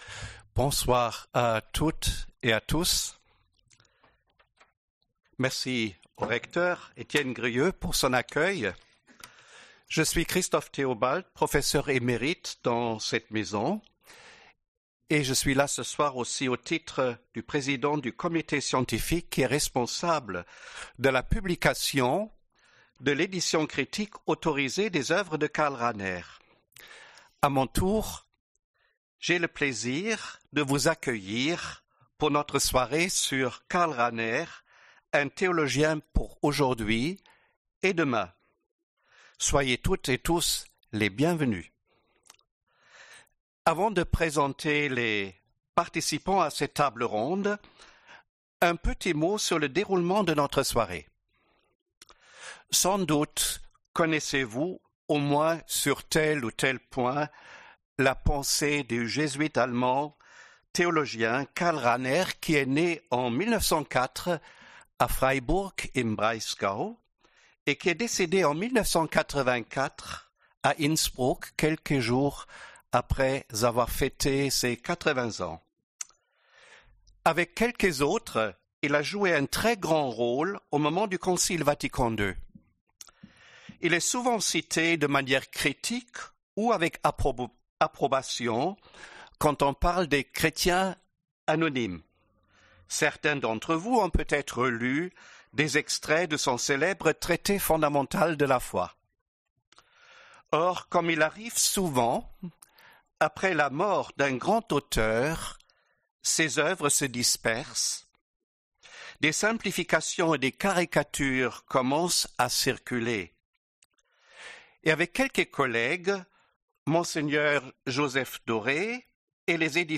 Soirée débat du 19 novembre 2021